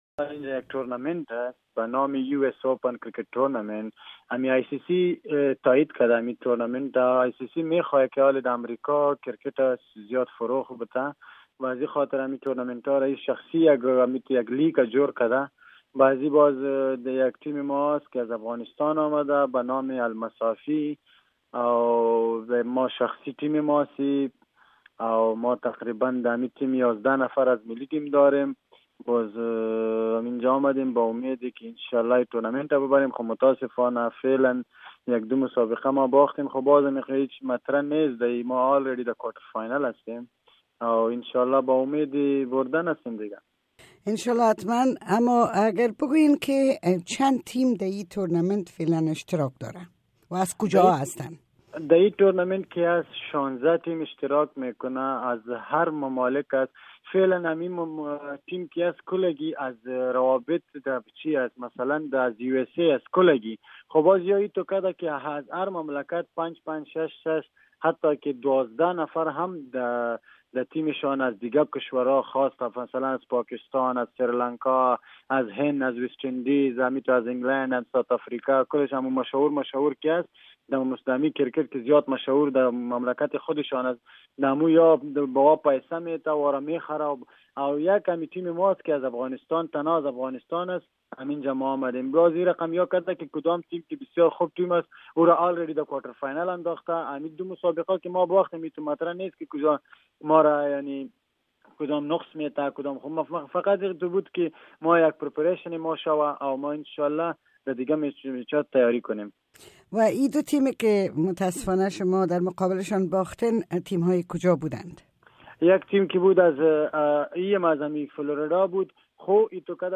توجه نمائید به مصاحبه صدای امریکا با جاوید احمدی.
مصاحبه با جاوید احمدی، عضو تیم ملی کریکت افغانستان